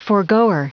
Prononciation du mot forgoer en anglais (fichier audio)
Prononciation du mot : forgoer